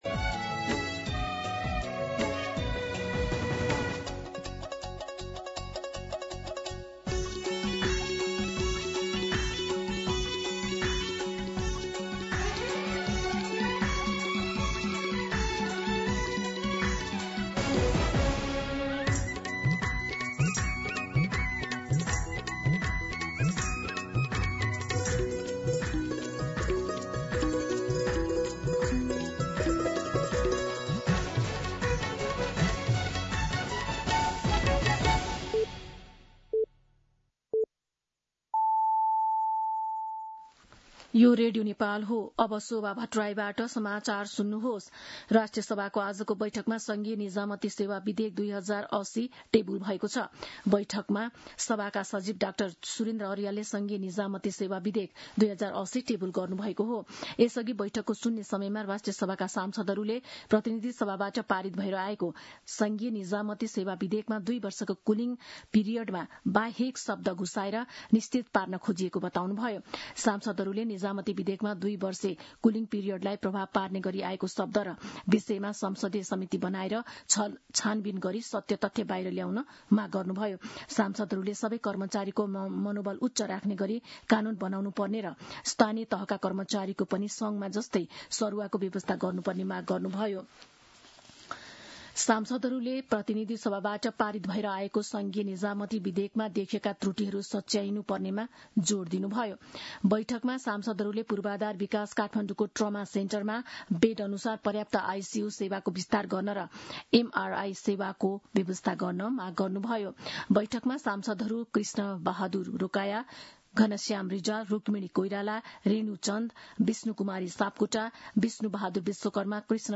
दिउँसो १ बजेको नेपाली समाचार : १८ असार , २०८२